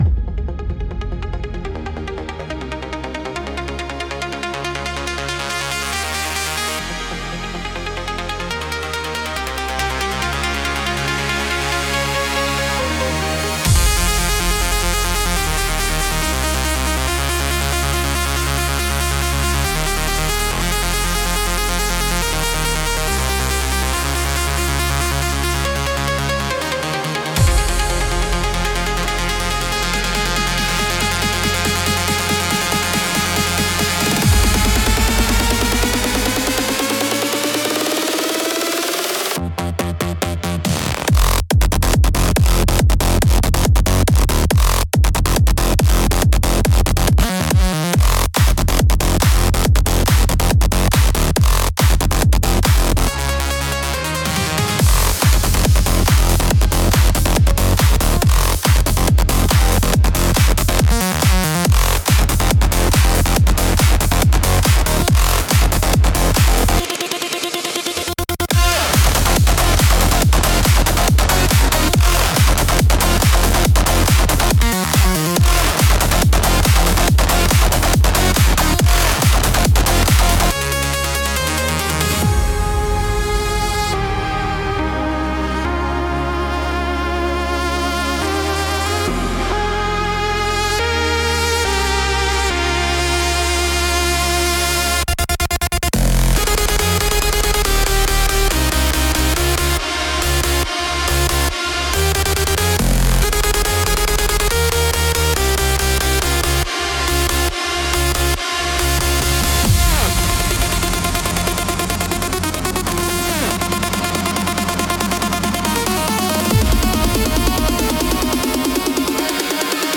high-voltage cyberpunk EDM anthem